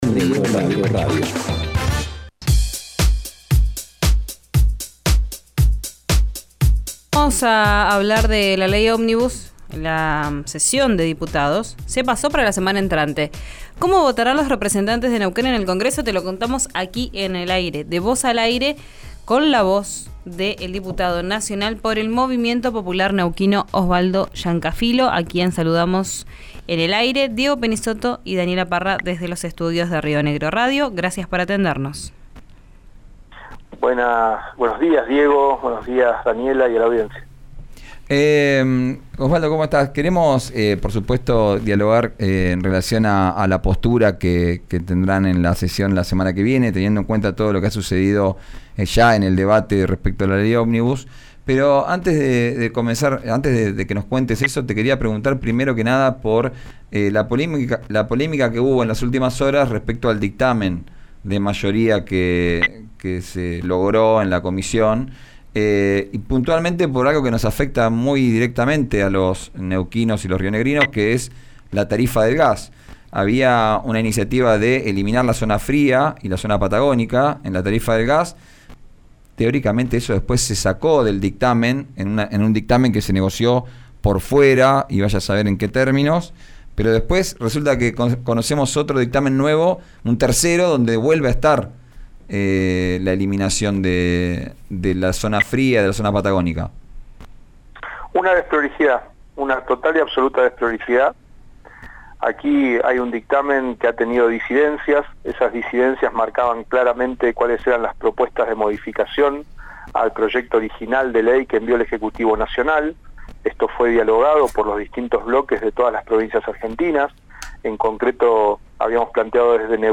El diputado nacional, Osvaldo Llancafilo, sostuvo que 'no se puede alterar dictámenes que ya fueron definidos' y aseguró que no negociará la zona fría. Escuchá la entrevista en RÍO NEGRO RADIO.